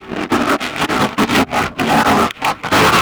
MONSTERS_CREATURES
ALIEN_Communication_15_mono.wav